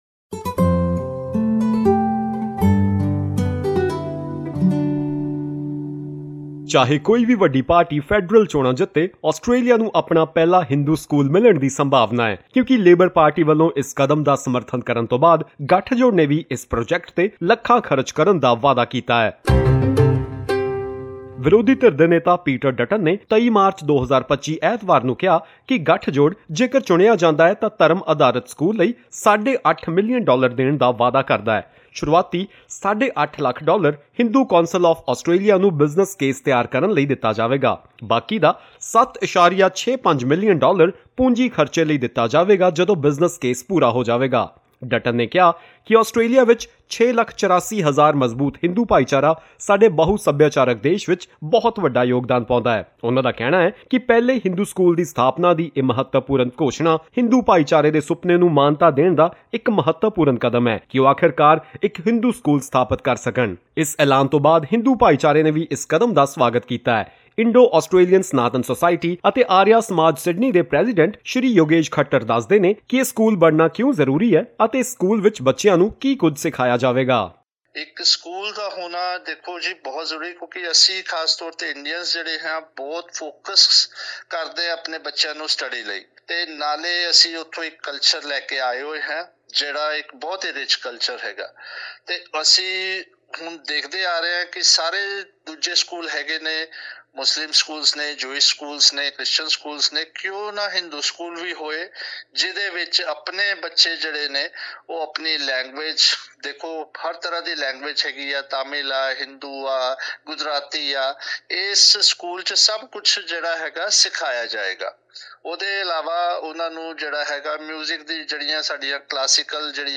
What are the full details, and what do community representatives have to say about this promise? Listen to this report to find out.